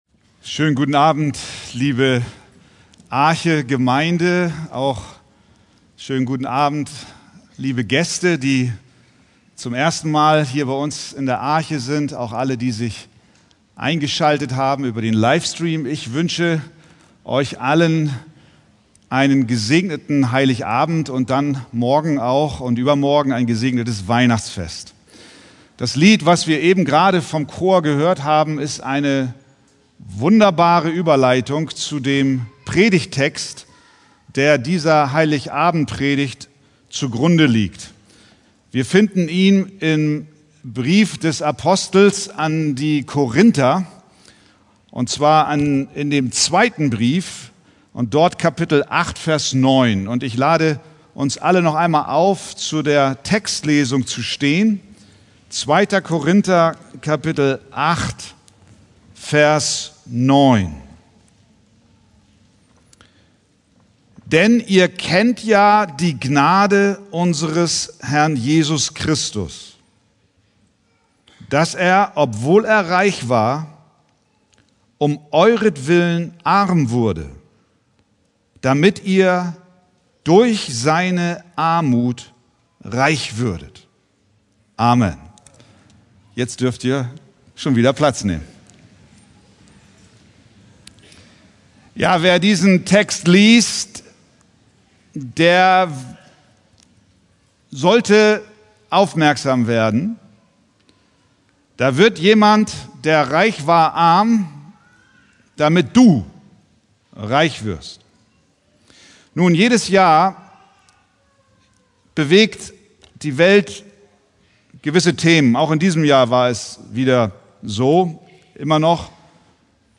Heiligabendgottesdienst | 2.